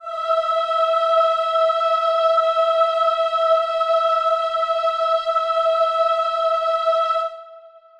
Choir Piano (Wav)
E5.wav